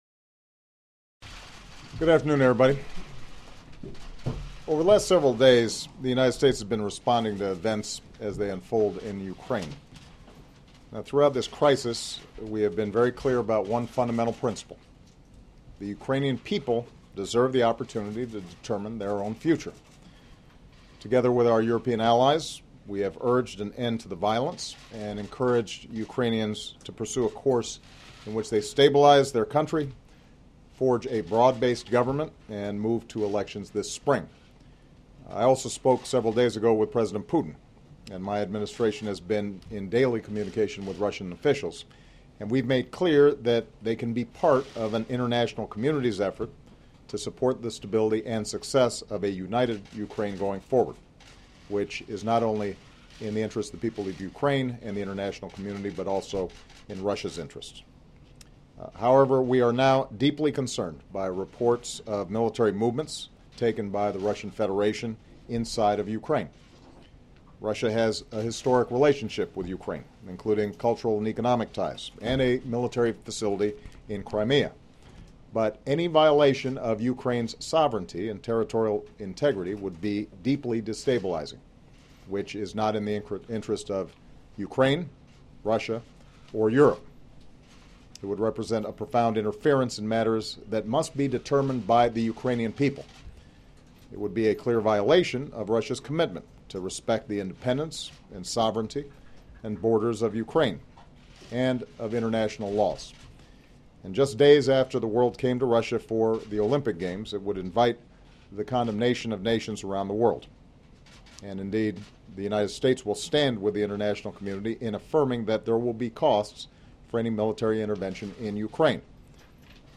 U.S. President Barack Obama delivers a statement about events unfolding in Ukraine
President Obama delivers a statement about events unfolding in Ukraine and reaffirms the United States will continue to coordinate closely with European allies and communicate directly with the Russian government. He declares the Ukrainian people have a right to self determination. Held in the James S. Brady Press Briefing Room in the White House.